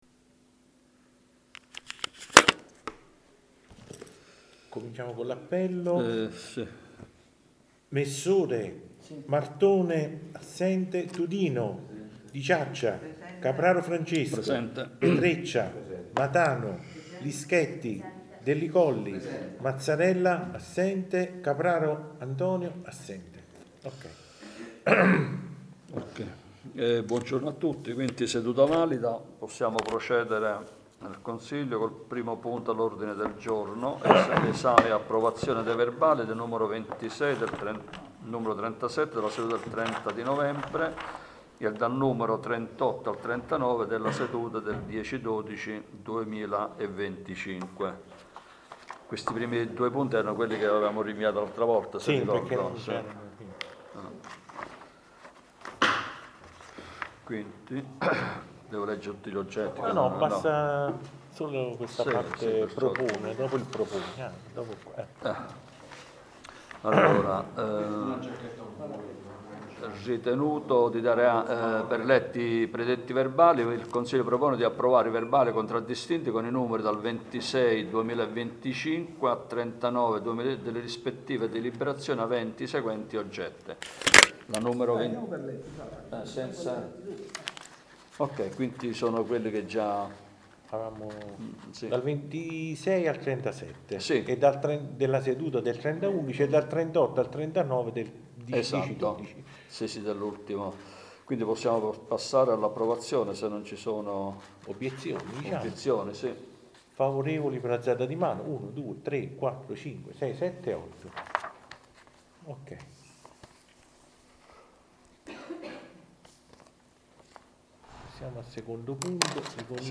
Registrazione seduta consiliare del 30.12.2025